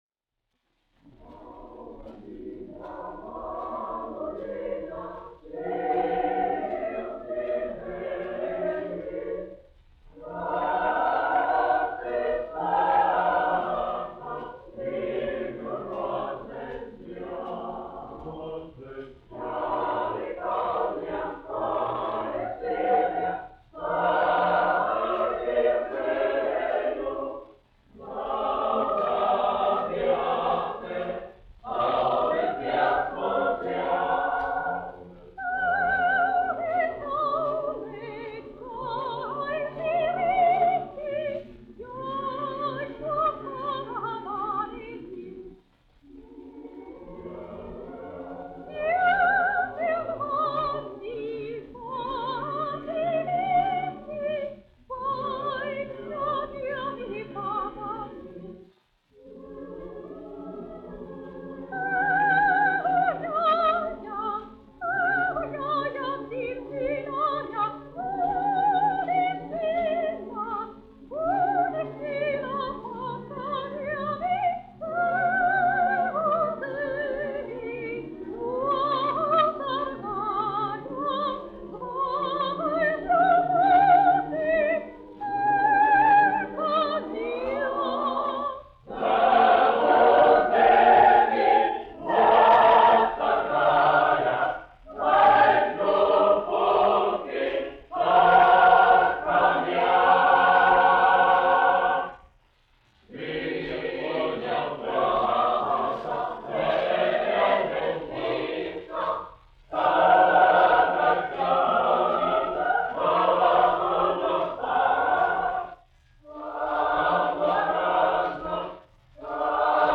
Latvijas Radio Teodora Kalniņa koris, izpildītājs
Kalniņš, Teodors, 1890-1962, diriģents
1 skpl. : analogs, 78 apgr/min, mono ; 25 cm
Kori (jauktie)
Skaņuplate